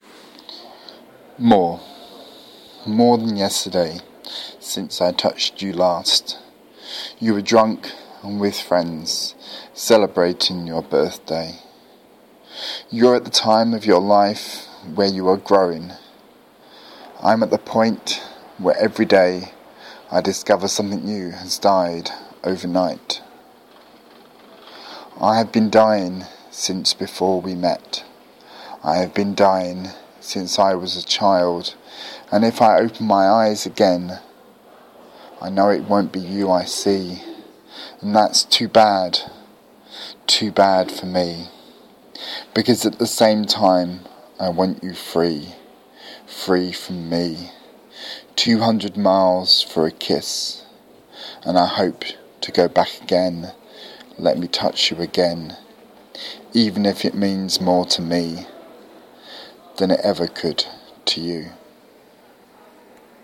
Dark love poetry